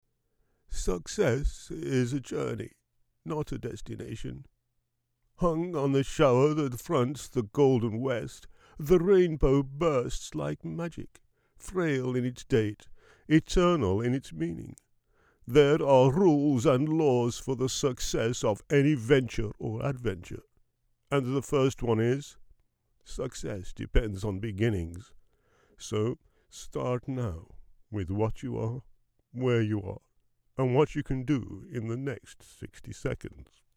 Tags: Voice Talent st.patrick saint patrick Voice actor